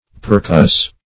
Percuss \Per*cuss"\ (p[~e]r*k[u^]s"), v. t. [imp. & p. p.